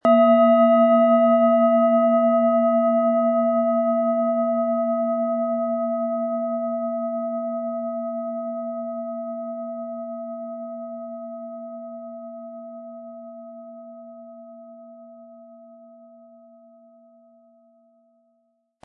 Tibetische Schulter- und Kopf-Klangschale, Ø 13,2 cm, 500-600 Gramm, mit Klöppel
Aber dann würde der ungewöhnliche Ton und das einzigartige, bewegende Schwingen der traditionellen Herstellung fehlen.
HerstellungIn Handarbeit getrieben
MaterialBronze